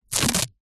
Звуки аптечки
Звук пластыря на ране